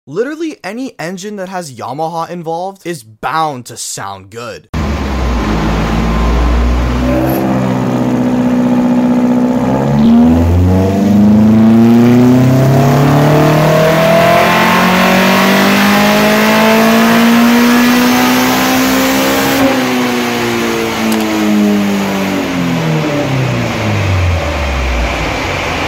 SICK ITB BEAMS 3S GE COROLLA, sound effects free download
Mp3 Sound Effect SICK ITB BEAMS 3S-GE COROLLA, PURE SOUND!!